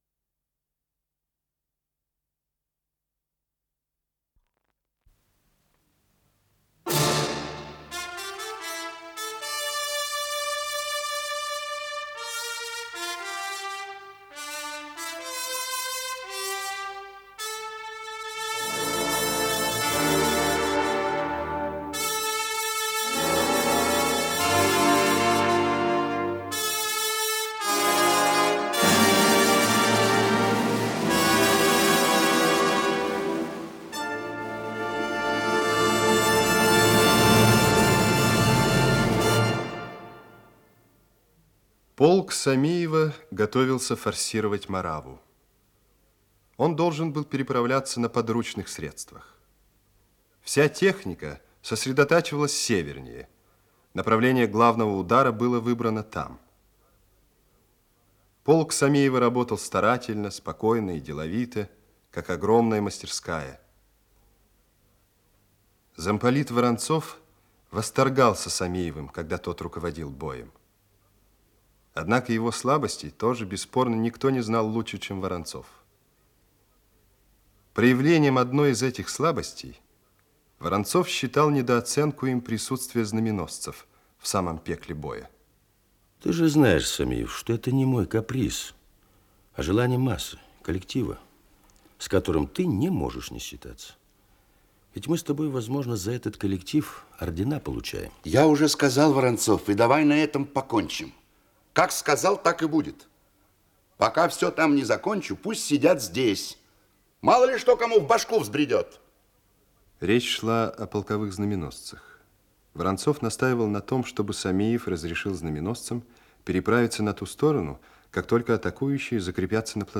Исполнитель: Артисты московских театров